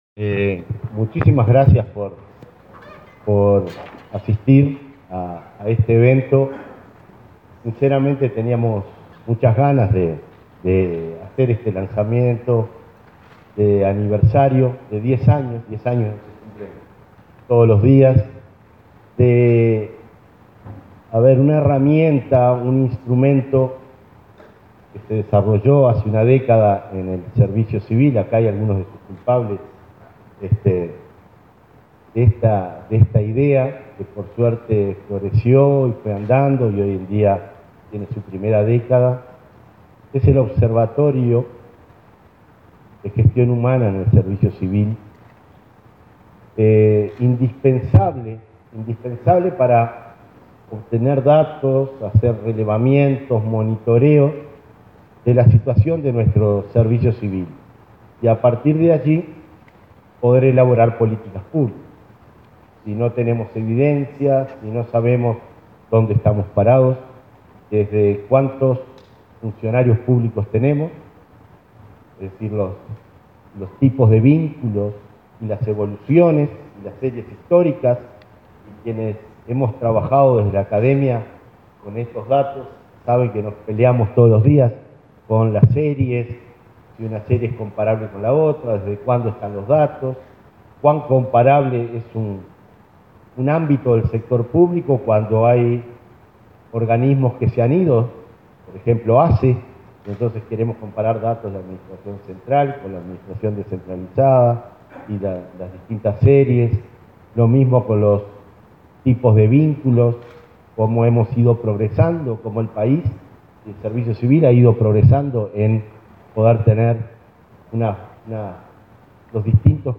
Observatorio de la Gestión Humana es una herramienta eficaz para la elaboración de políticas públicas, aseveró Conrado Ramos 30/11/2020 Compartir Facebook X Copiar enlace WhatsApp LinkedIn A diez años de la creación del Observatorio de la Gestión Humana, el director de la Oficina Nacional del Servicio Civil (ONSC), Conrado Ramos, ratificó la herramienta como un instrumento que permite monitorear la situación para elaborar políticas públicas. La celebración se llevó a cabo este lunes de 30, en Torre Ejecutiva, con la presencia del subdirector del organismo, Ariel Sánchez.